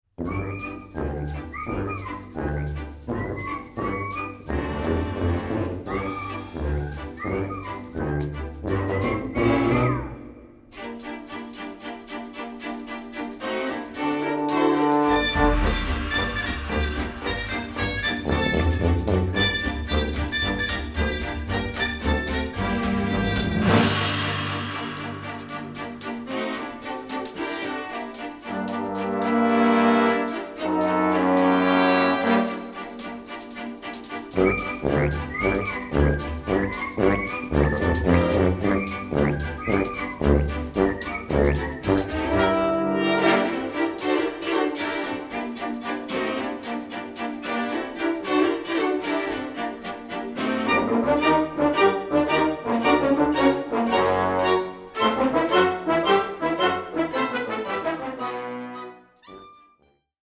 robust, full-blooded music